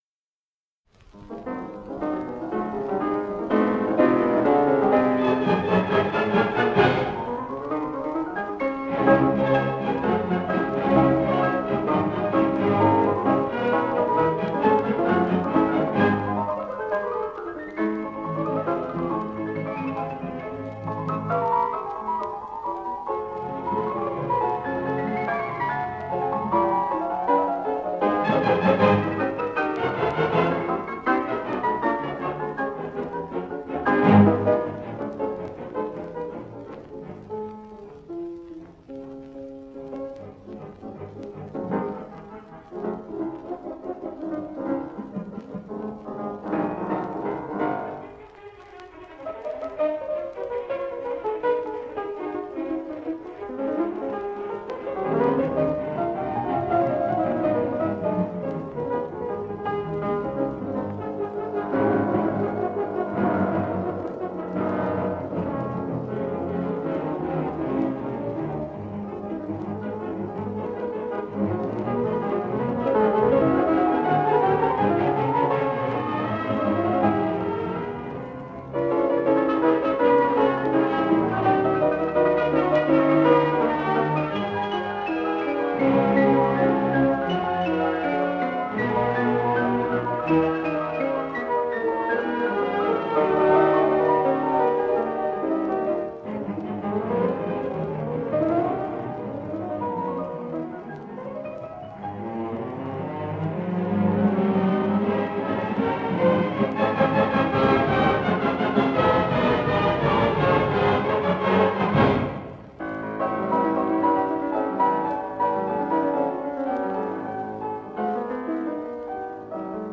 Concerto No. 2 in C minor, Op. 18.
The Gramophone Company. 1 disco : 78 rpm ; 30 cm.